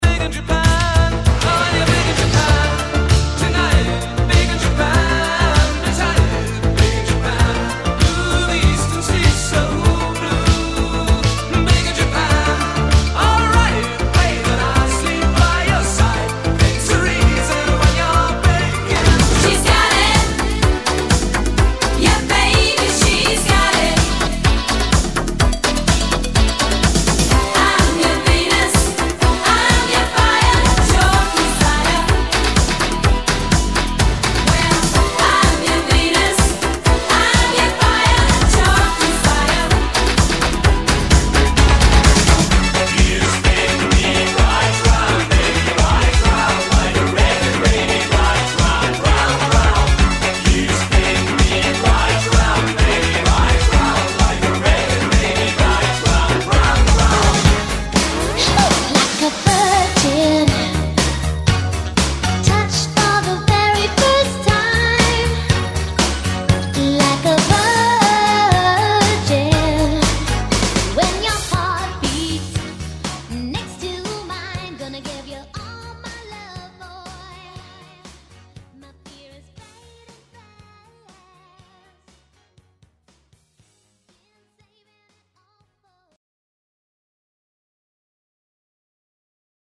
perfekt gemixten Beats